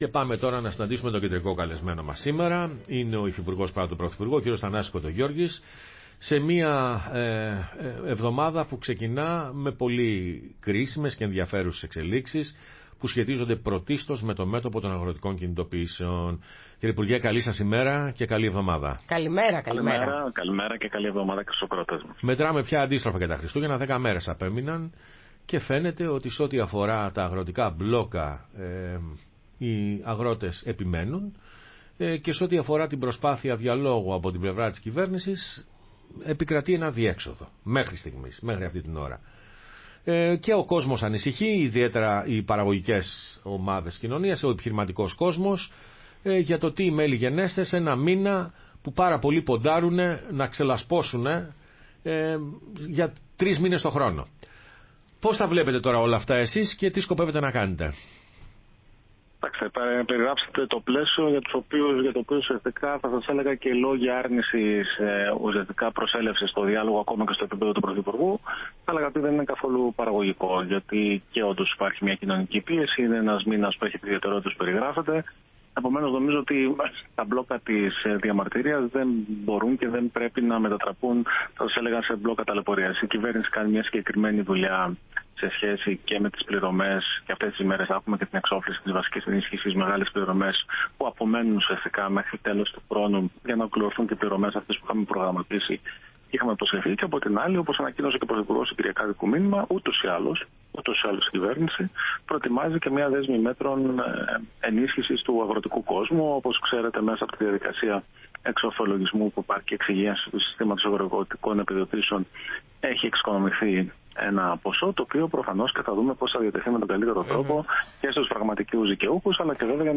Θανάσης Κοντογεώργης, Υφυπουργός παρά τω Πρωθυπουργώ, μίλησε στην εκπομπή “Πρωινές Διαδρομές ”